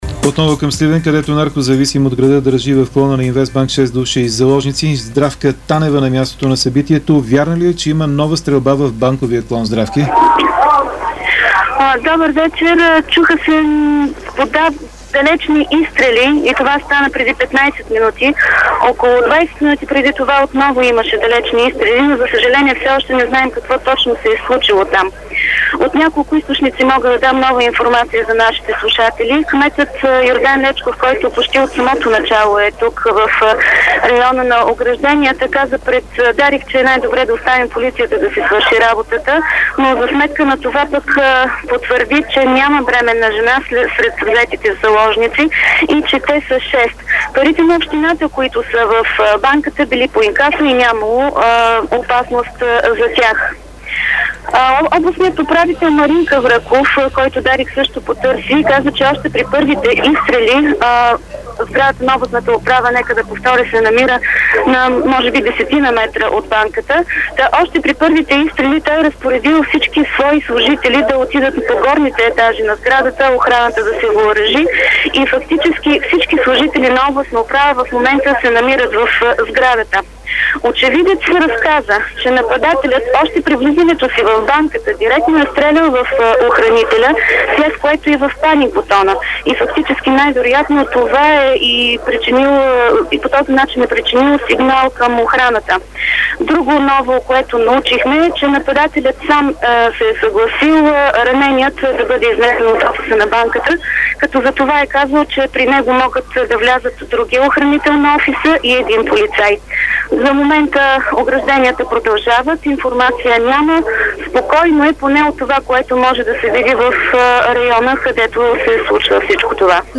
Репортаж от мястото на инцидента